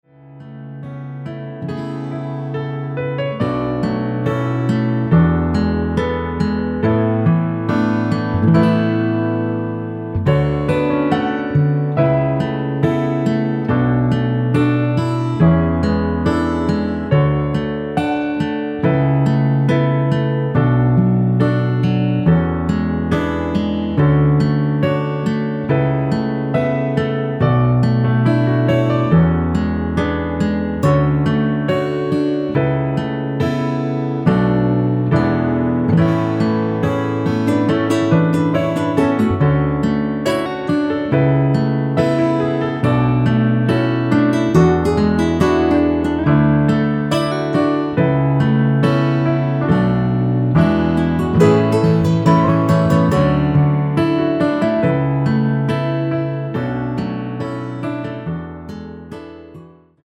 (-1) 내린1절후 후렴으로 진행되게 편곡 하였습니다.(아래의 가사 참조)
◈ 곡명 옆 (-1)은 반음 내림, (+1)은 반음 올림 입니다.
앞부분30초, 뒷부분30초씩 편집해서 올려 드리고 있습니다.
중간에 음이 끈어지고 다시 나오는 이유는